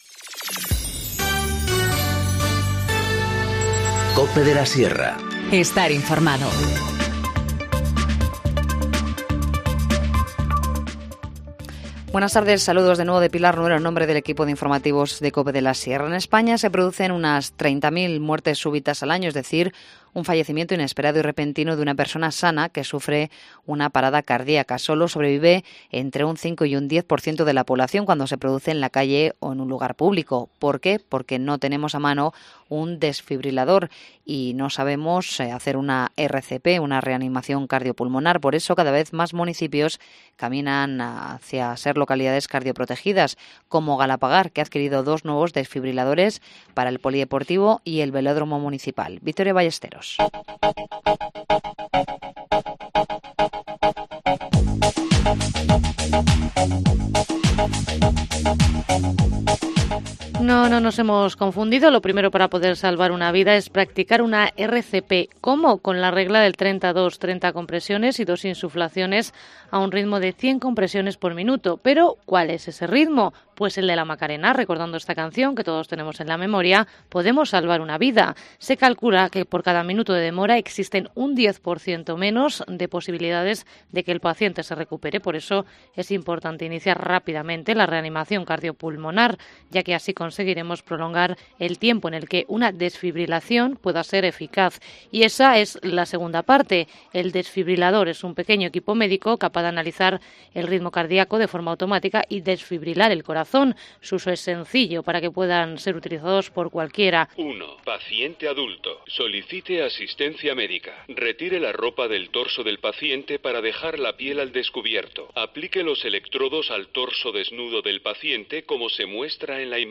Informativo Mediodía 12 marzo 14:50h